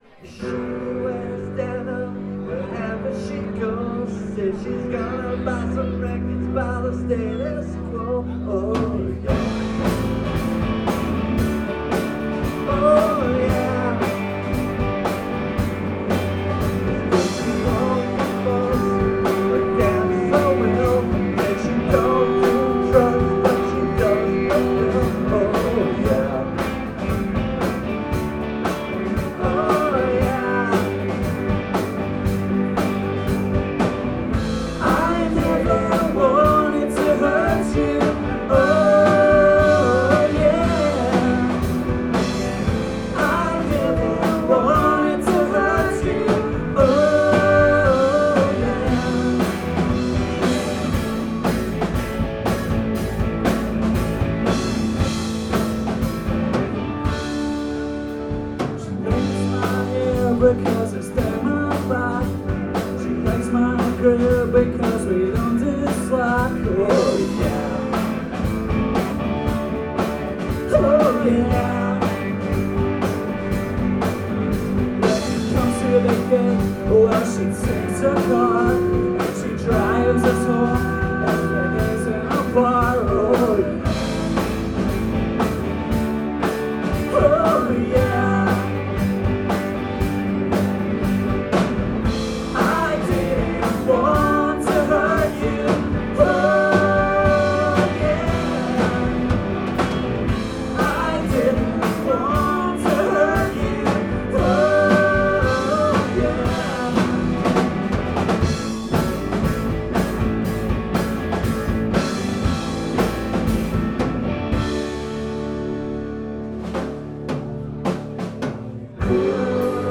Its a pretty fun cover, with a lot of energy
guitar and harmonies